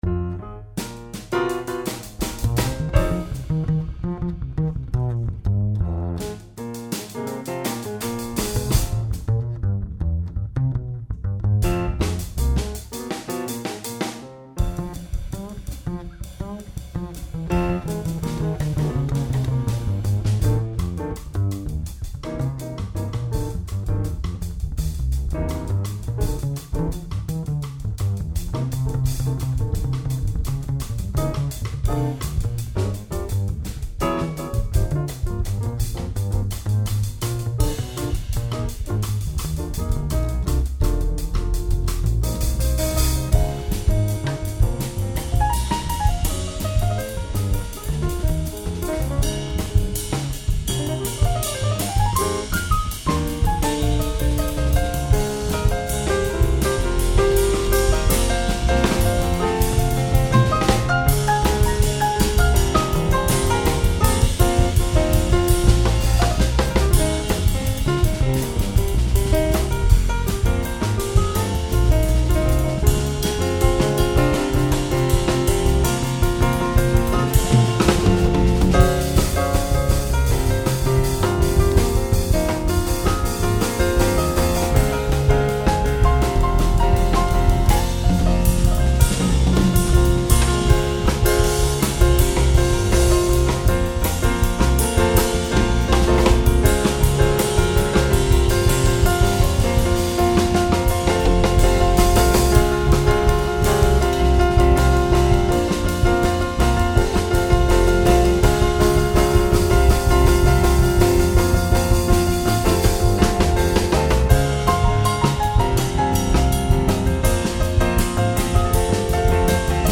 Piano
Double Bass